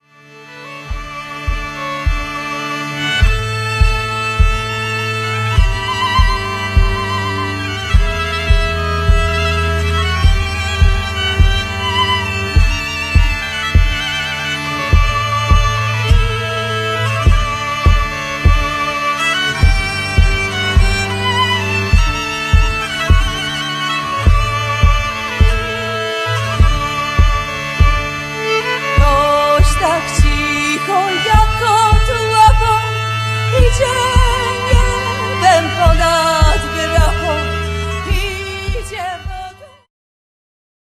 bębny, instrumenty perkusyjne
kontrabas
cymbały, gitara, koboz, mandolina
mandola, saz, gitara, lira korbowa, śpiew